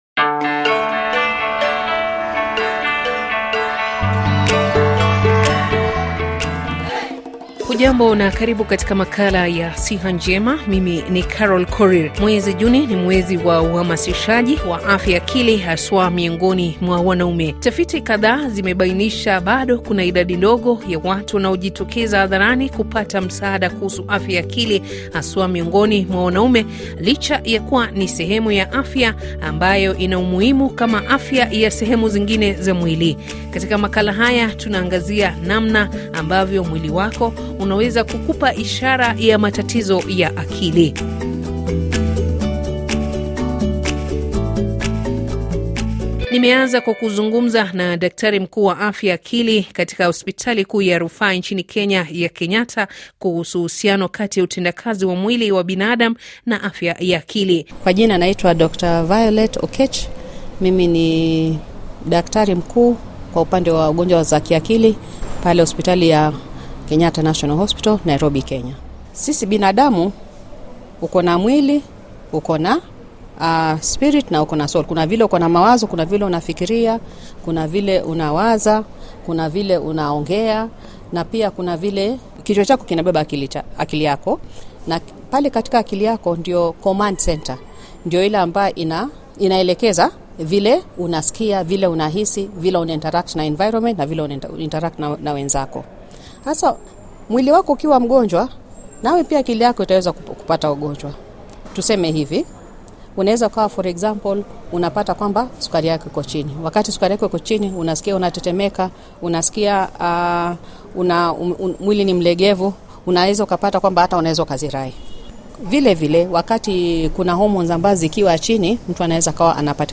Makala inayojadili kwa kina masuala ya afya, tiba na kutambua magonjwa mbalimbali bila ya kusahau namna ya kukabiliana nayo. Watalaam walibobea kwenye sekta ya afya watakujuvya na kukuelimisha juu ya umuhimu wa kuwa na afya bora na si bora afya. Pia utawasikiliza kwa maneno yao watu ambao wameathirika na magonjwa mbalimbali na walikumbwa na nini hadi kufika hapo walipo.